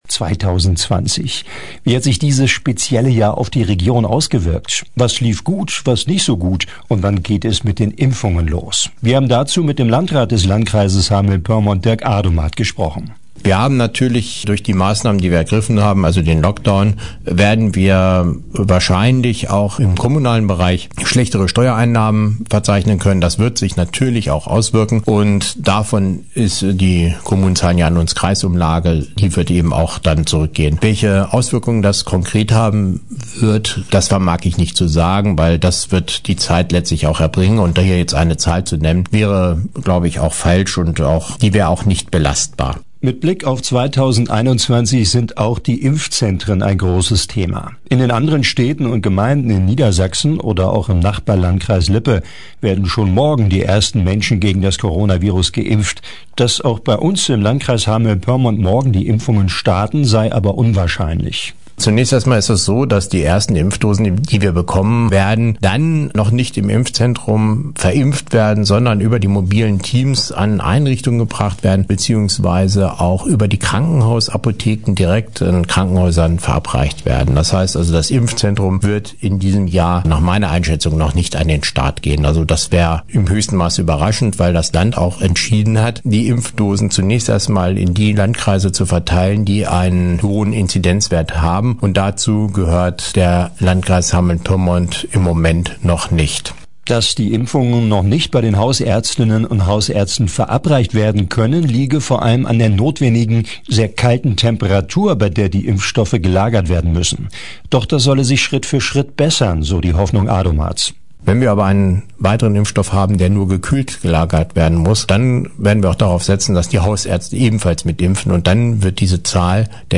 Und wann geht es mit den Impfungen los? Das ganze Interview mit Landrat Dirk Adomat können Sie bei uns morgen am Sonntag hören, in der Sendung „Nachgefragt“ ab 10 Uhr…